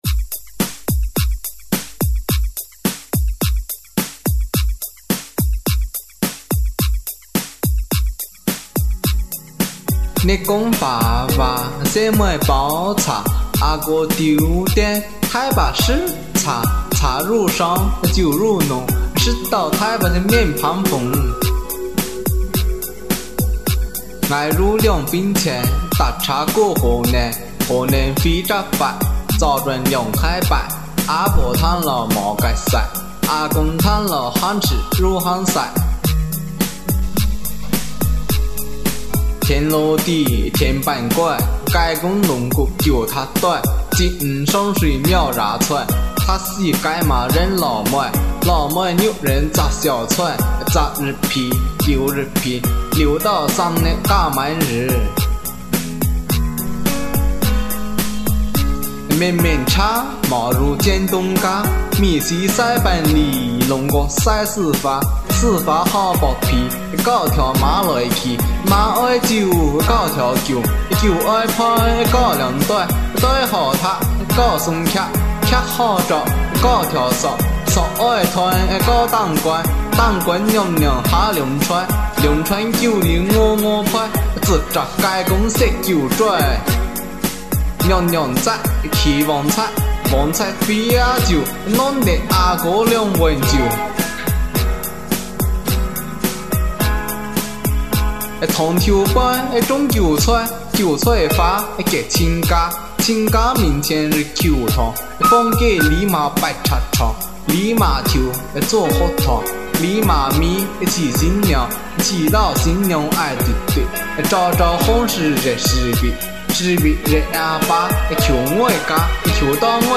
兴宁口音朗诵的客家童谣2集